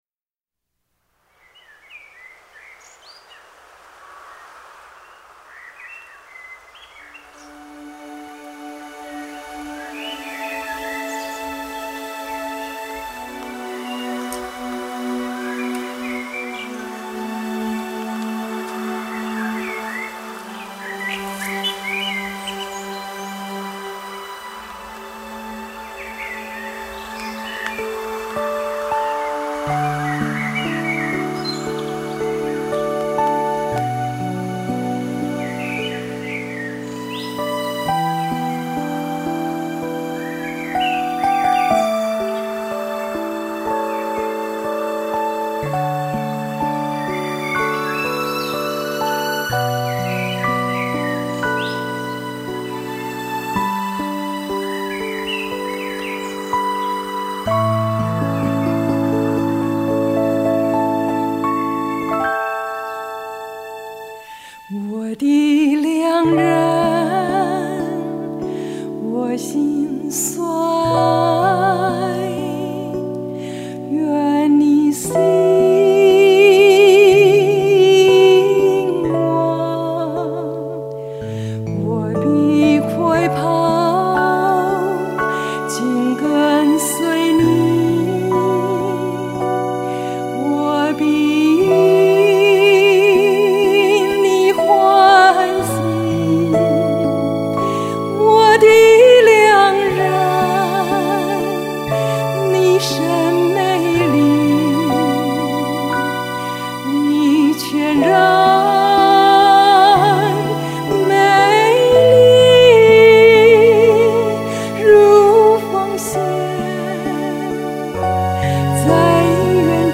D調4/4